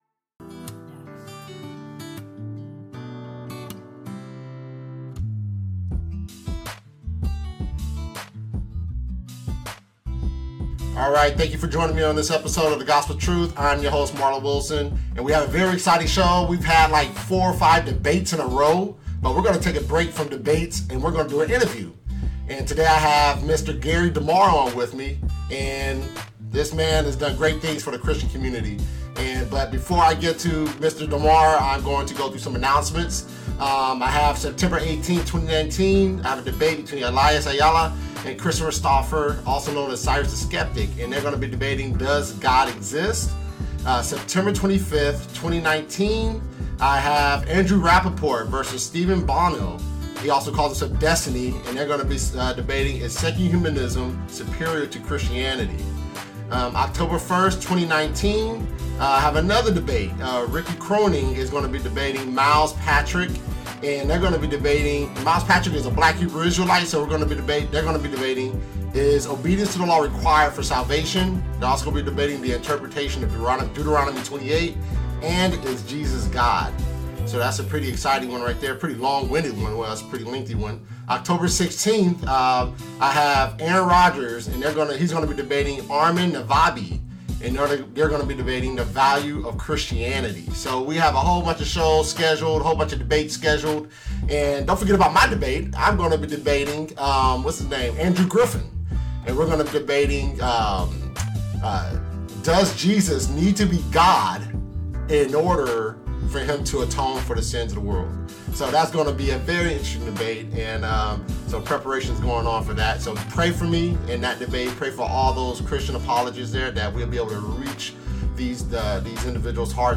Interview: God and Government.